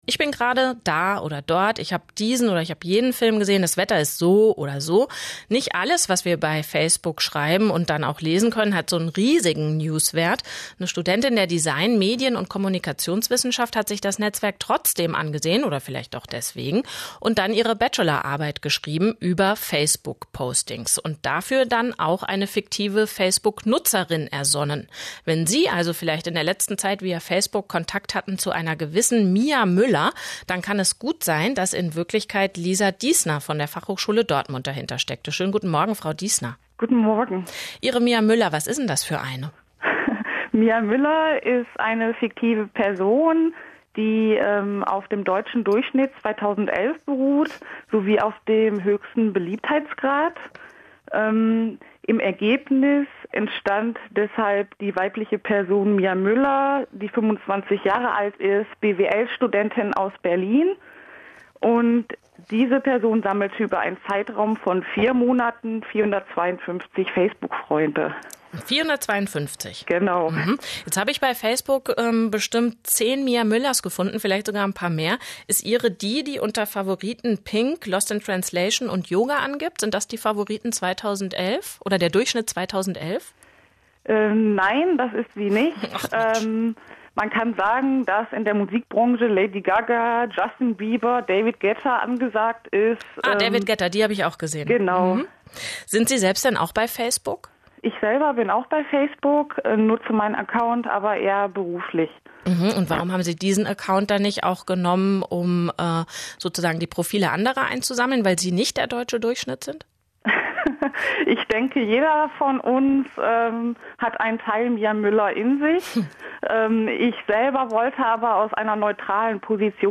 RBB Radiointerview zu meiner Bachelorarbeit